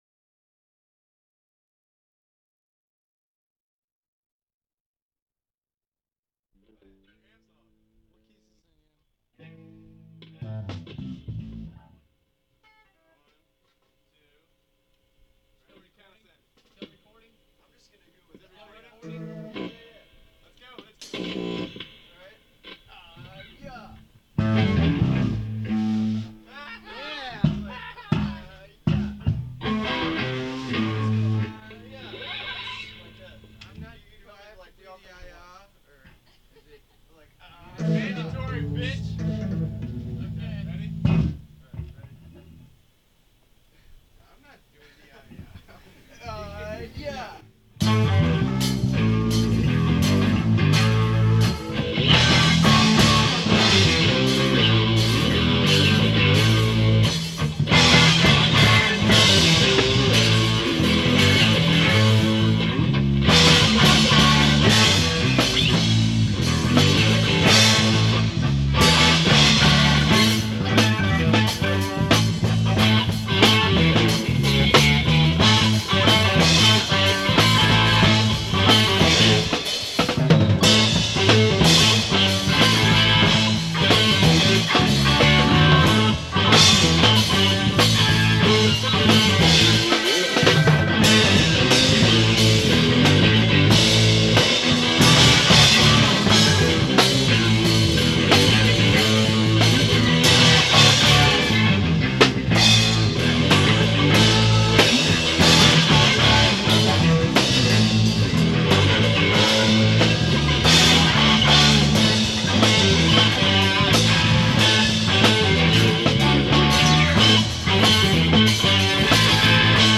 The reporter dubbed our sound revved-up blues rock.